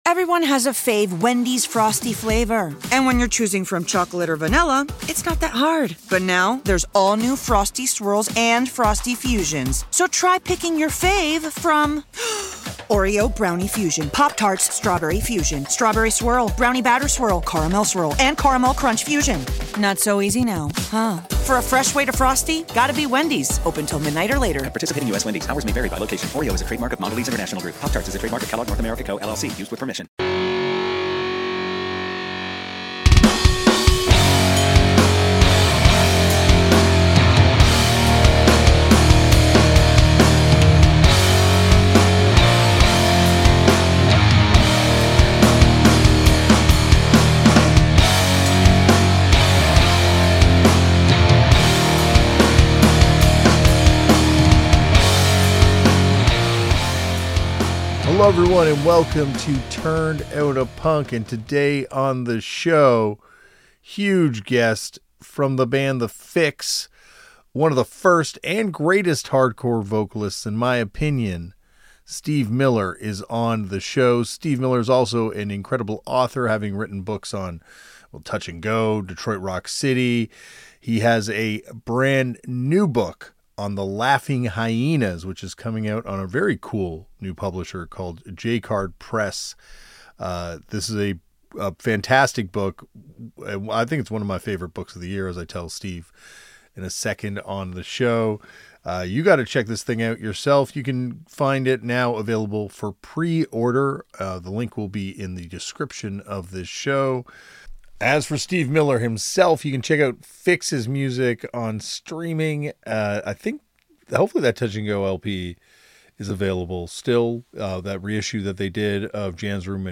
Music Interviews, Music History, Music, Music Commentary